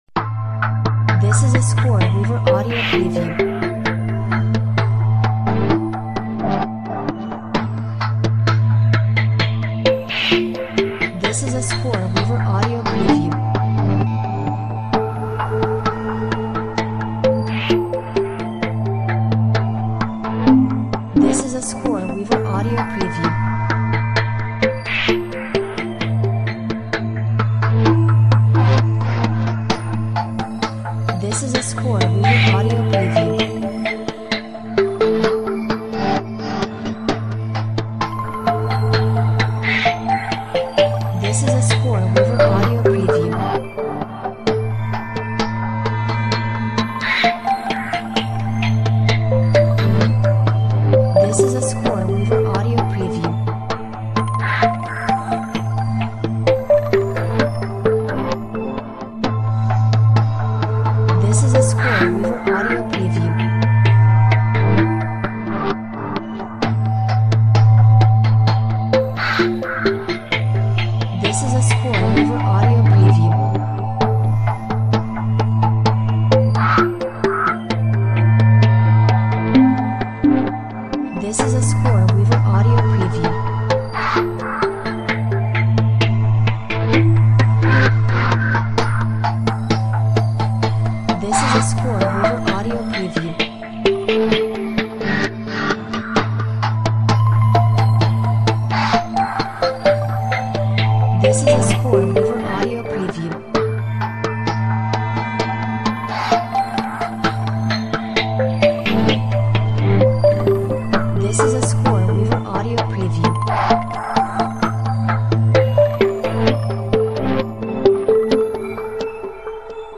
Mysterious and futuristic science theme background music.